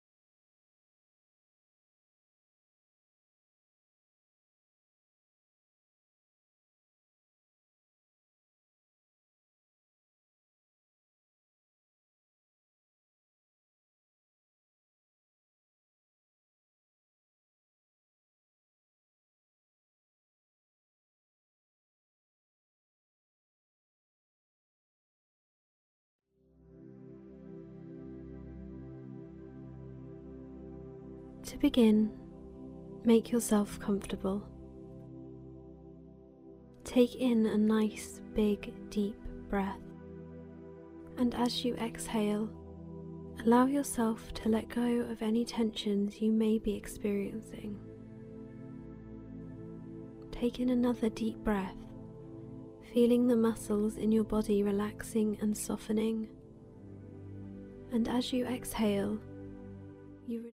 Law of Attraction Meditation – produced in a way that deeply connects with the subconscious mind to increase the accuracy & time frame in which manifestations show up in your life. If the audio seems sluggish or awkward to you, realize that it has been designed in a very deliberate way so as to trigger your mind to search deeply for ways to produce ultimate outcomes for you.
Musical composition by Christopher Lloyd Clarke.